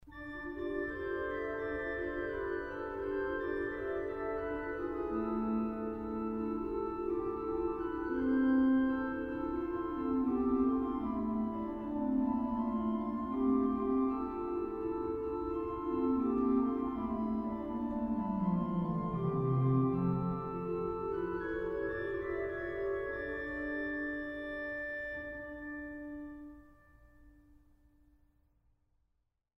Kaps-Orgel in Mariä Himmelfahrt zu Dachau